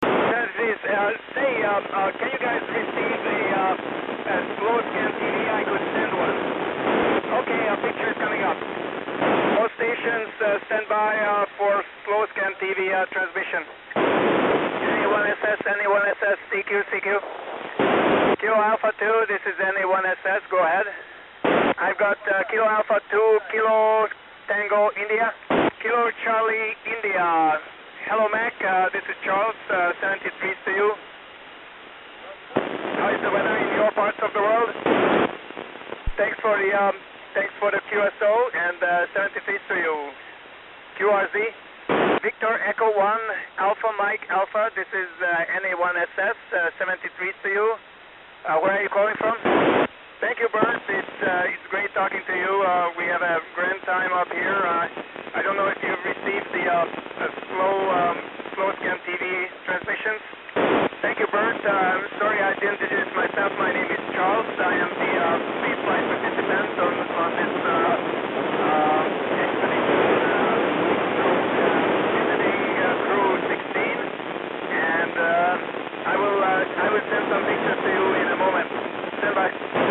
Charles-NA1SS-calling CQ and stby for SSTV
Charles getting ready to send SSTV during pass over Eastern Canada